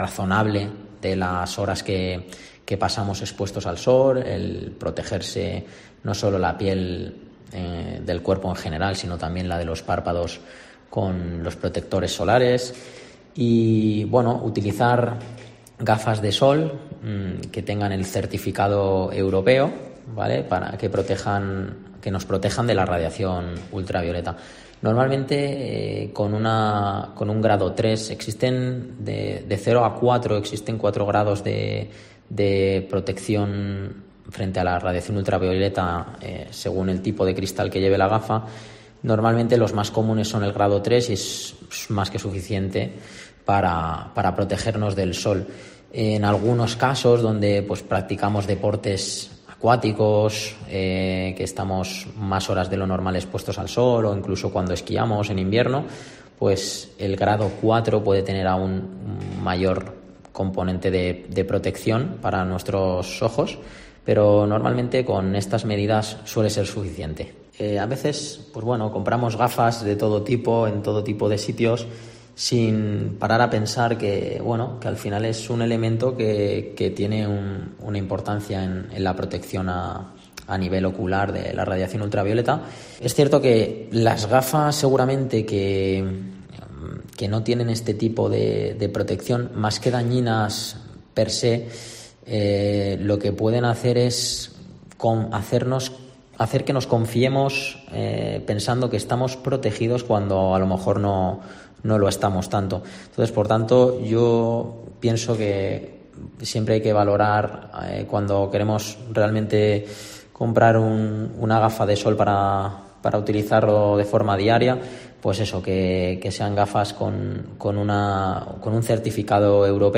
oftalmólogo